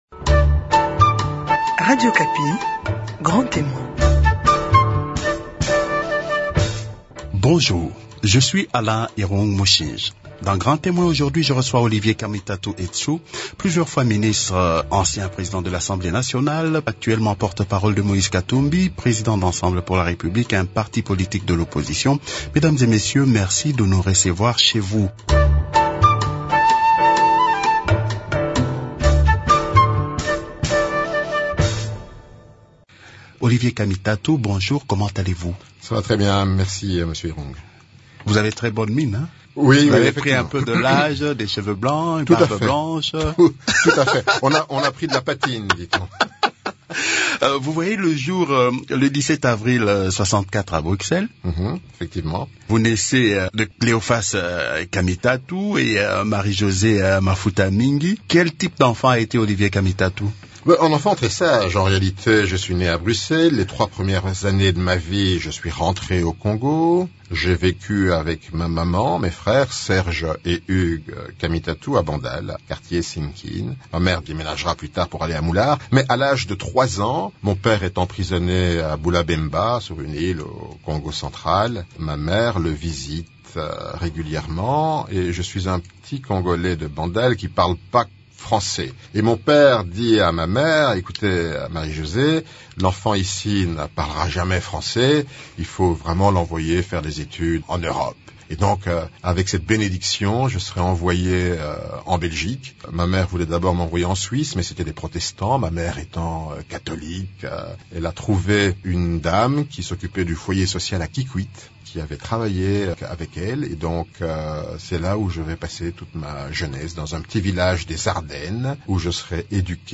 Grand Témoin reçoit Olivier Kamitatu Etsu, plusieurs fois ministres, ancien président de l’Assemblée nationale, ancien président du parti politique Alliance pour le Renouveau du Congo (ARC), actuellement porte-parole de Moïse Katumbi président du parti Ensemble pour la République, membre de l’opposition.